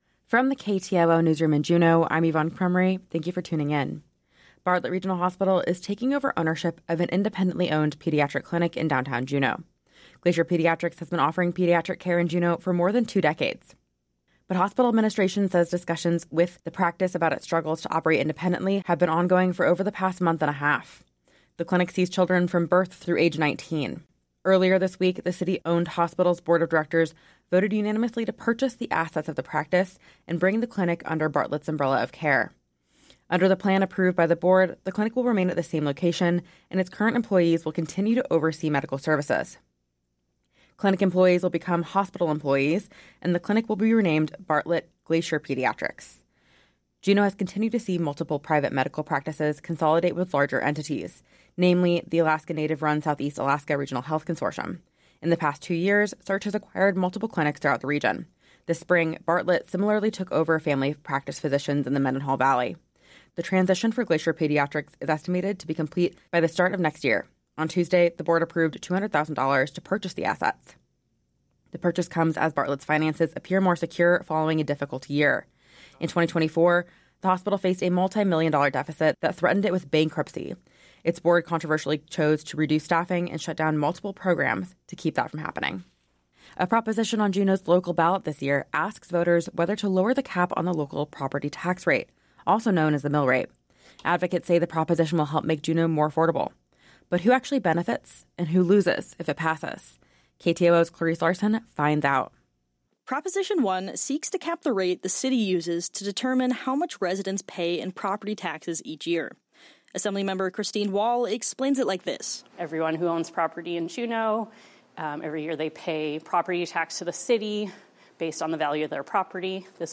Newscast – Friday, Sept. 26, 2025 - Areyoupop